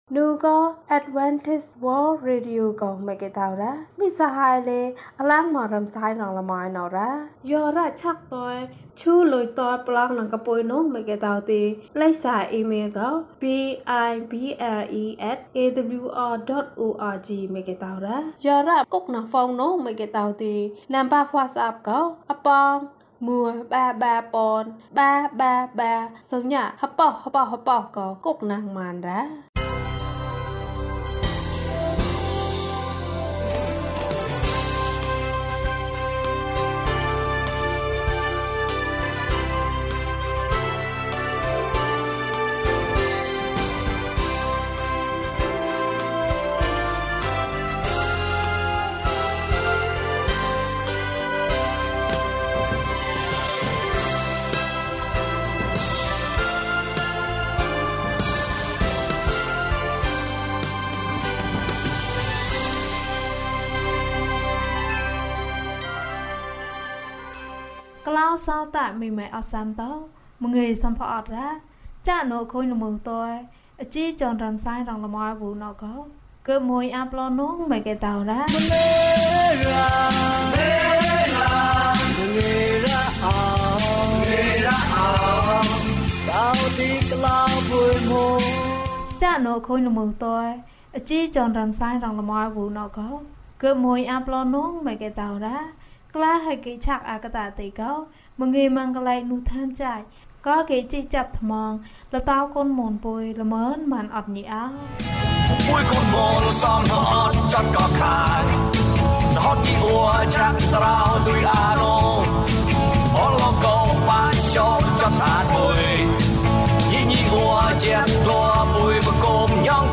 လုံခြုံရေးကောင်းသည်။၀၂ ကျန်းမာခြင်းအကြောင်းအရာ။ ဓမ္မသီချင်း။ တရားဒေသနာ။